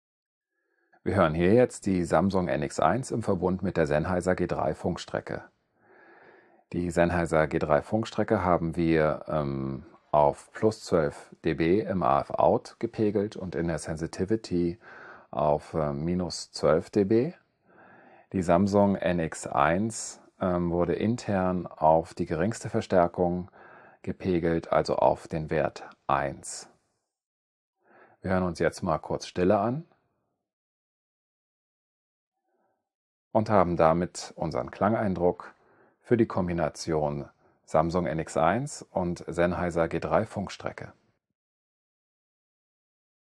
Hier inklusive Denoiser:
Samsung NX1 mit Sennheiser G3 Funkstrecke und Rode Lavalier
SamsungNX1_SennheiserG3_norm_denoise.wav